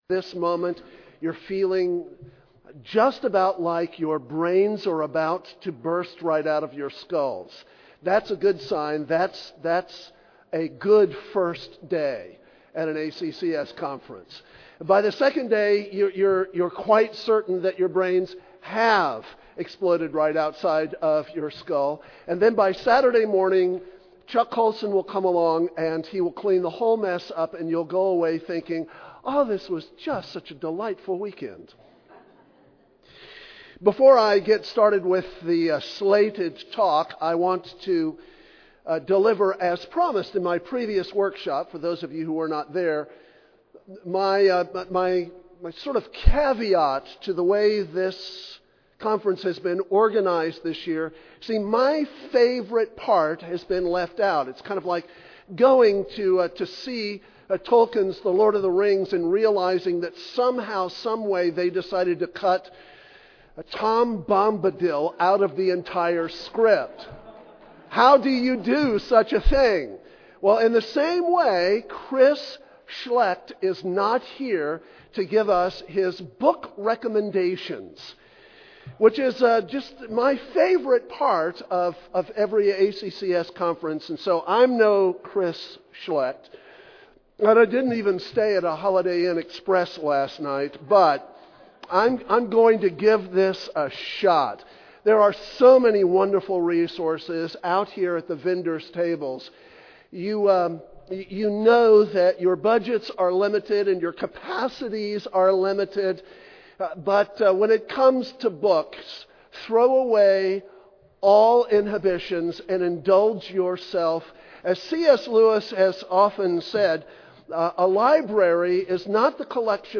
2009 Workshop Talk | 1:00:07 | All Grade Levels, History